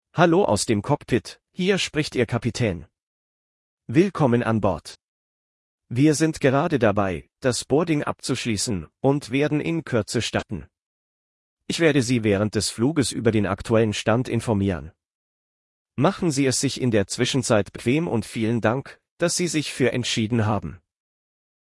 BoardingWelcomePilot.ogg